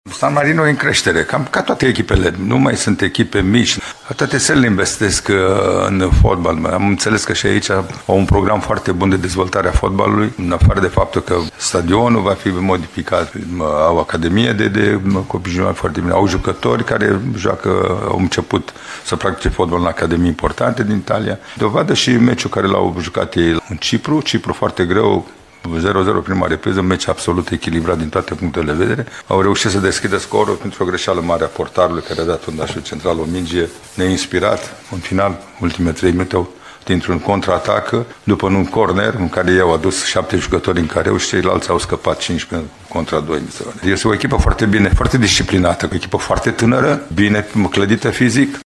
Mircea Lucescu a vorbit și despre adversarul de astăzi, San Marino, despre care a spus că s-a dezvoltat mult în ultimii ani: